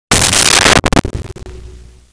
Low Tom Hit 01
Low Tom Hit 01.wav